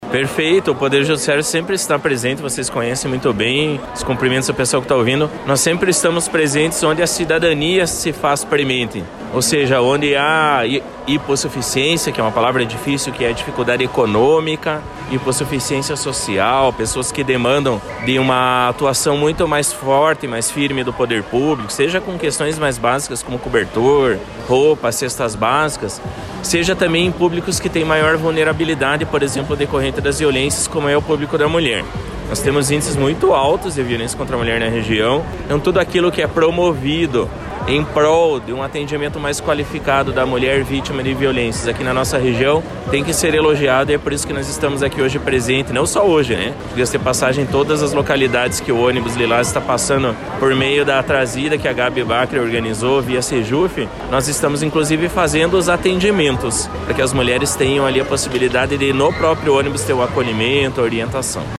O Coordenador do CEJUSC e juiz da Vara da Família, Carlos Mattioli, falou sobre o atendimento do poder judiciário no Ônibus Lilás.